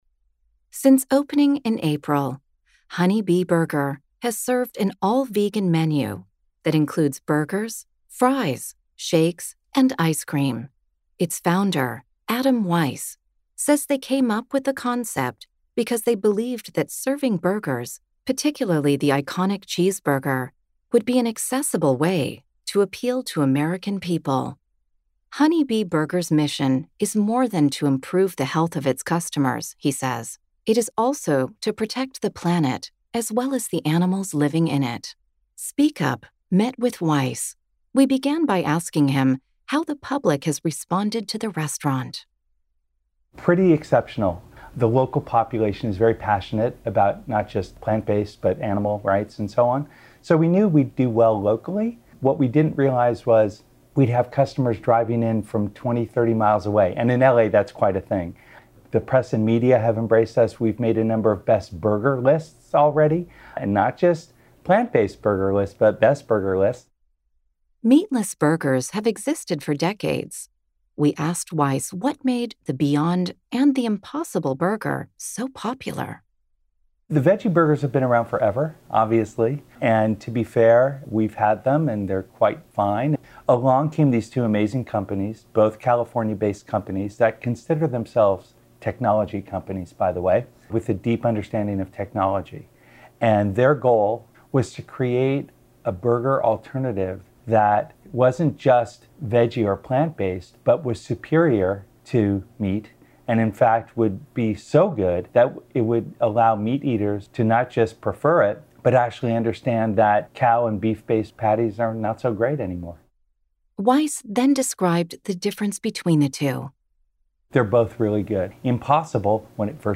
(American accent)